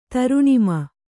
♪ taruṇima